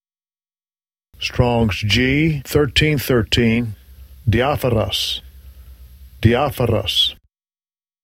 Phiên âm quốc tế: dē-ä’-fo-ros Phiên âm Việt: đi-á-pho-rót